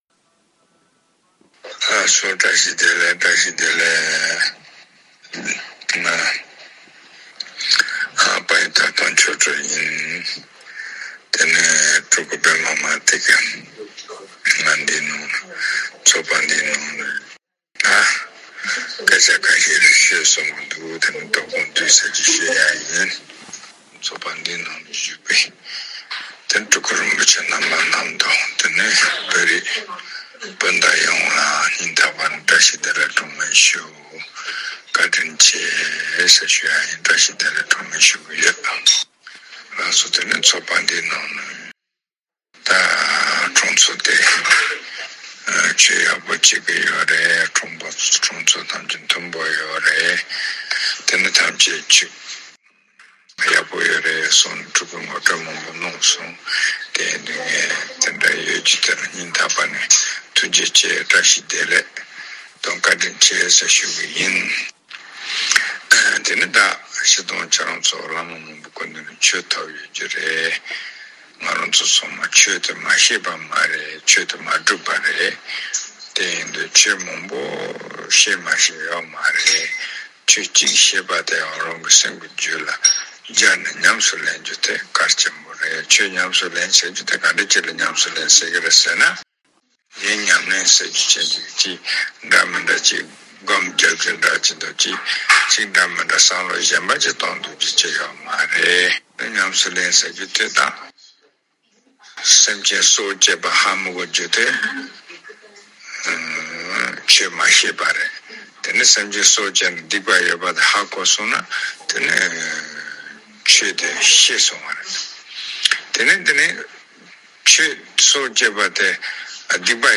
བཀའ་སློབ།